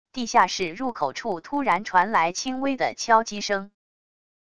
地下室入口处突然传來轻微的敲击声wav音频